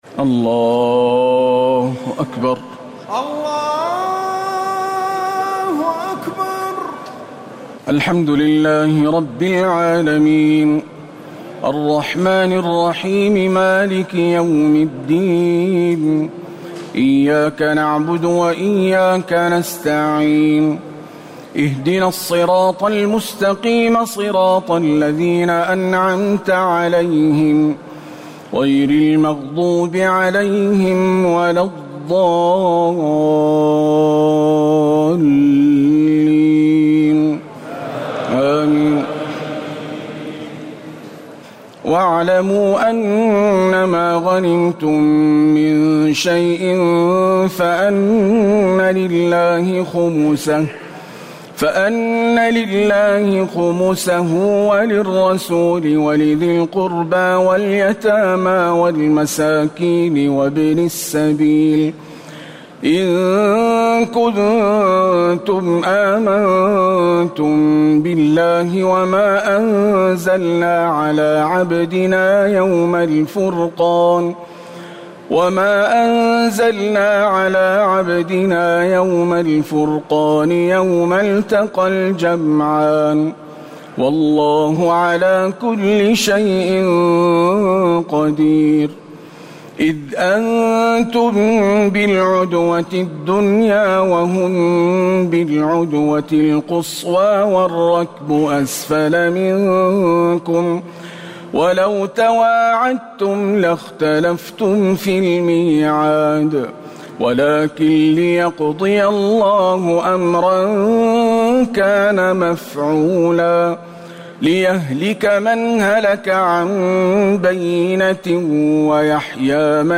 تراويح الليلة التاسعة رمضان 1439هـ من سورتي الأنفال (41-75) و التوبة (1-33) Taraweeh 9 st night Ramadan 1439H from Surah Al-Anfal and At-Tawba > تراويح الحرم النبوي عام 1439 🕌 > التراويح - تلاوات الحرمين